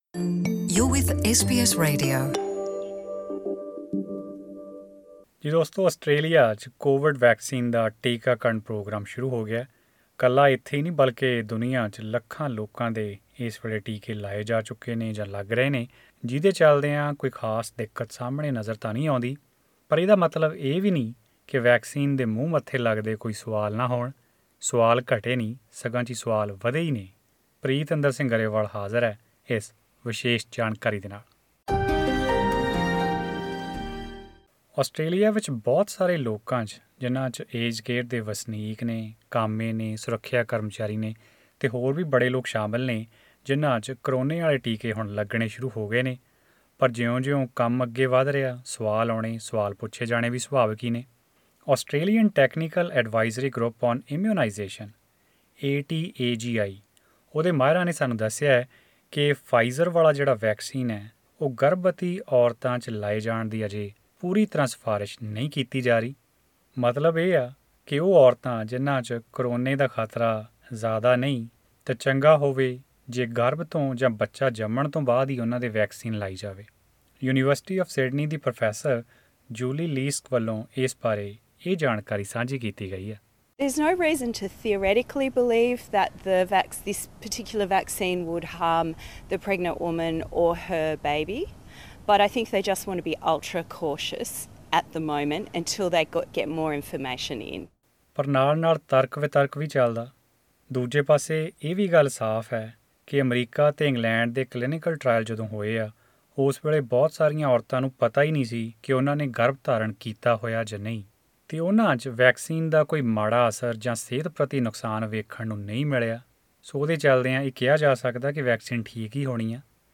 Listen to this audio report to find all the answers based on expert advice.